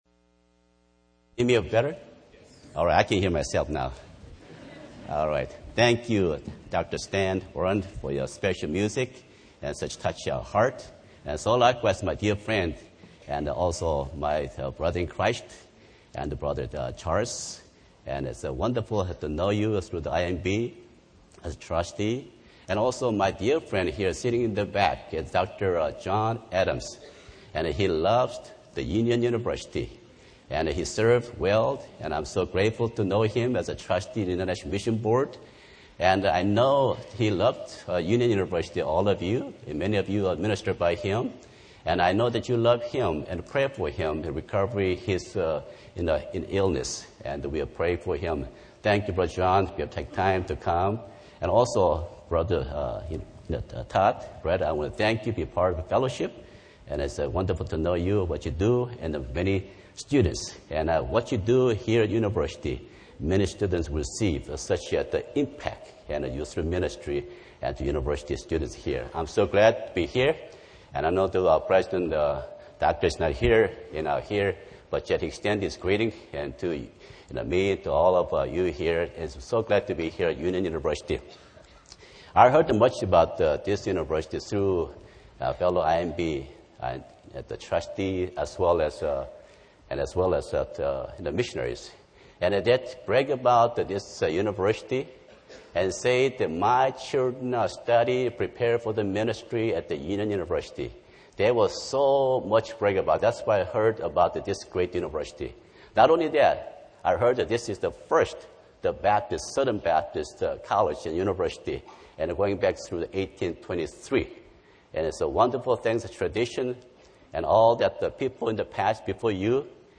The Union Audio Project | Union University, a Christian College in Tennessee